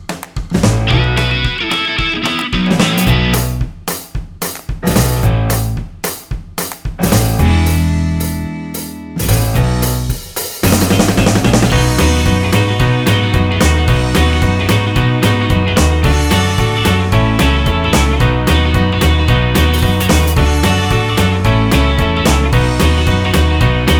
no Backing Vocals Rock 'n' Roll 3:21 Buy £1.50